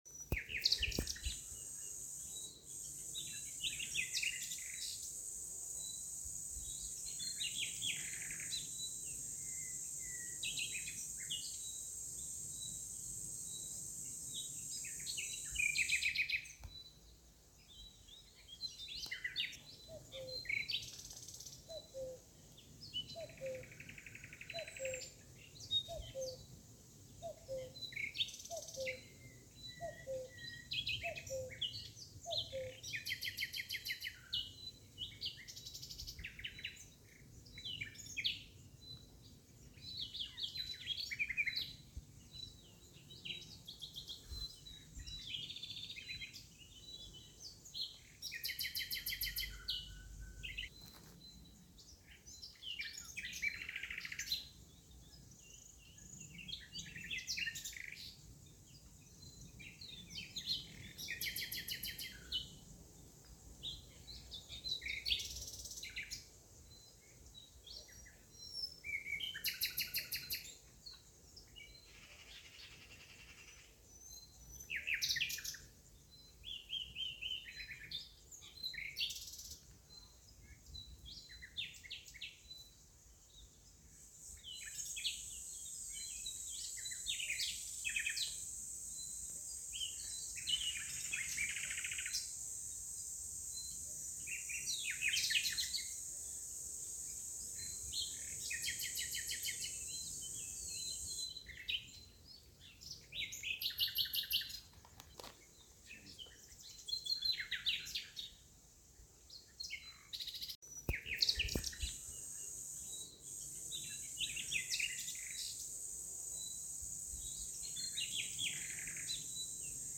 Głośnik automatyczny, MERDIA AROMA SOUND BOX, relaksujący śpiew ptaków, stojący